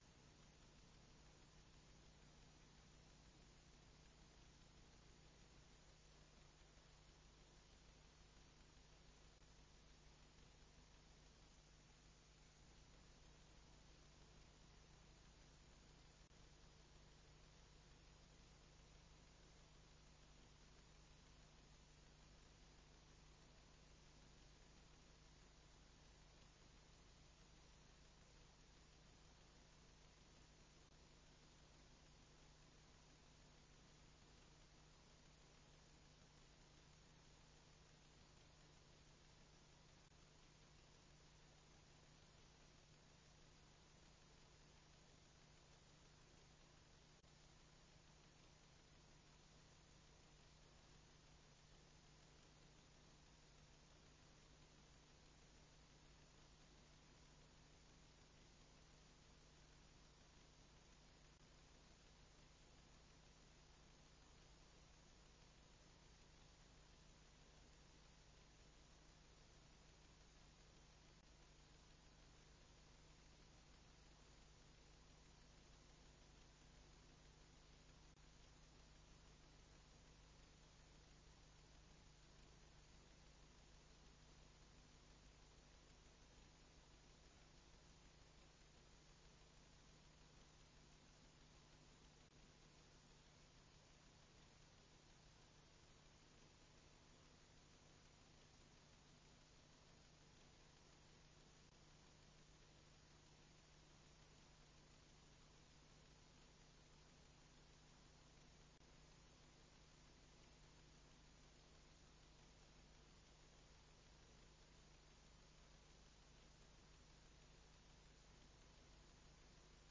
Město Litvínov: 26. zasedání Zastupitelstva města Litvínova 11.12.2025 c09cc39a2982f21bb533bd0c7fd37265 audio